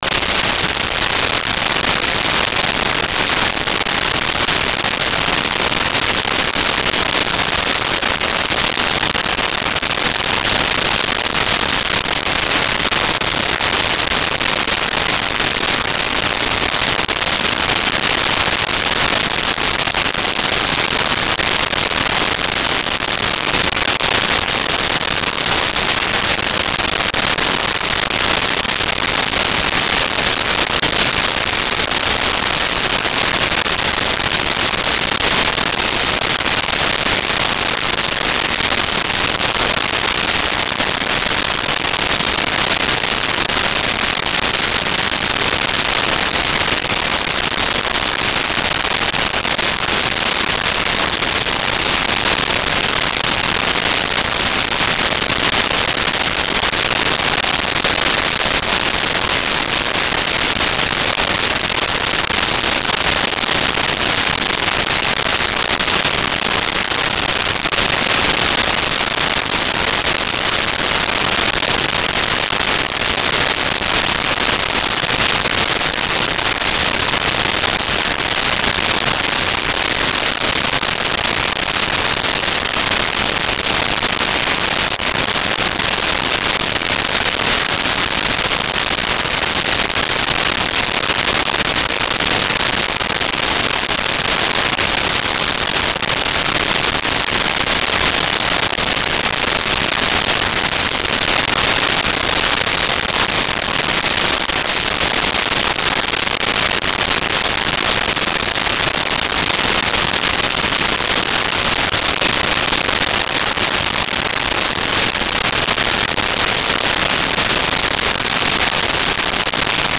Lake Waves Lapping
Lake Waves Lapping.wav